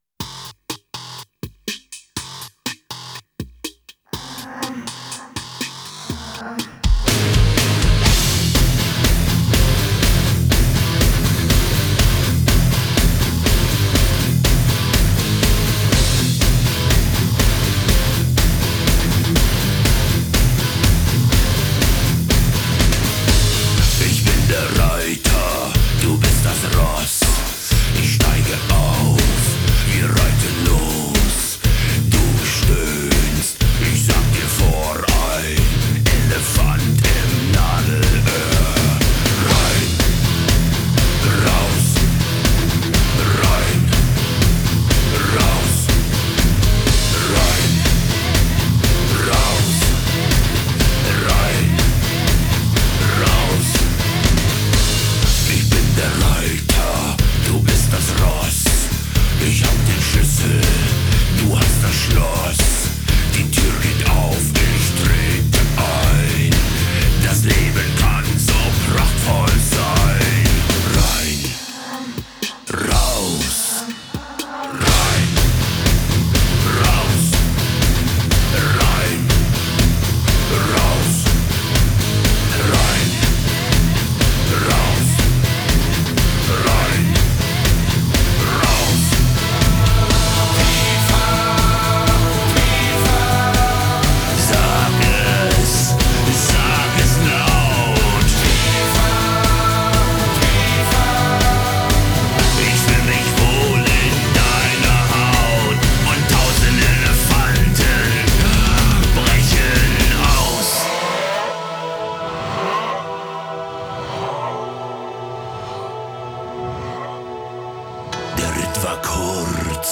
Жанры: Neue Deutsche Herte, индастриал-метал,
хард-рок, готик-метал